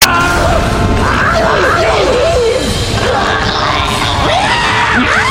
نغمة بيانو رومنسي
Sound Effects